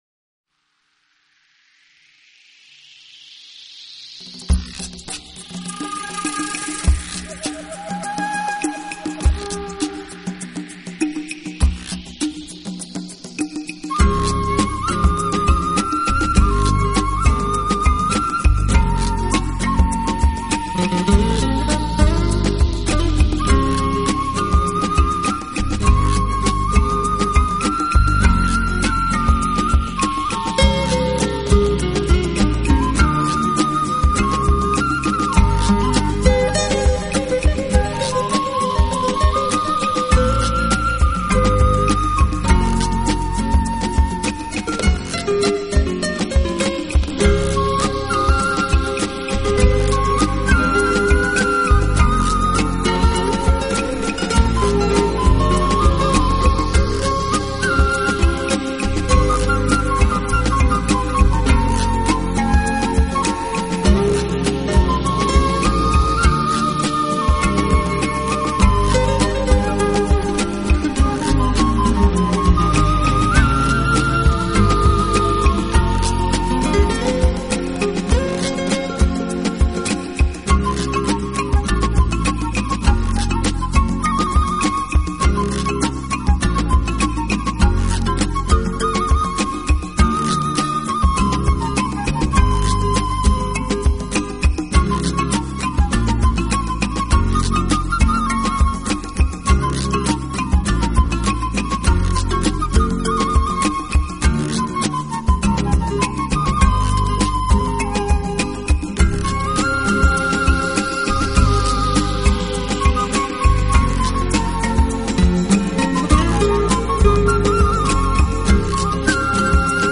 【纯音乐】世界器乐精选集VA